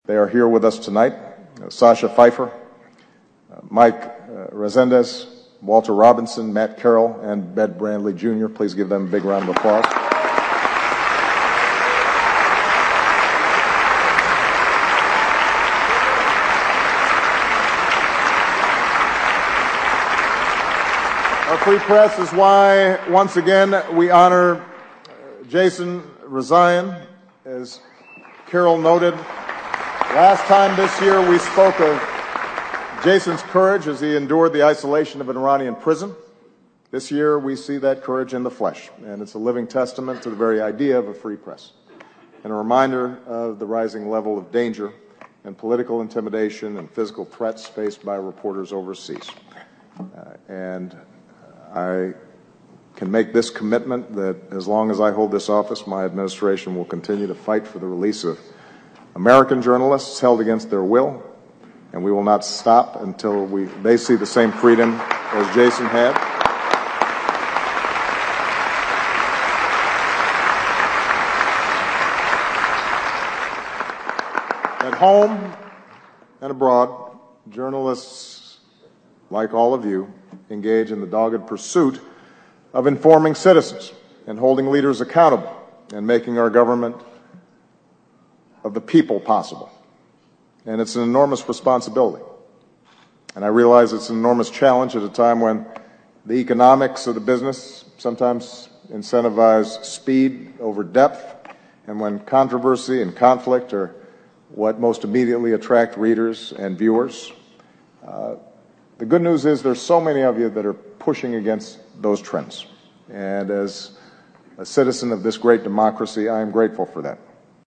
欧美名人演讲 第92期:奥巴马任内末次白宫记者晚宴演讲(14) 听力文件下载—在线英语听力室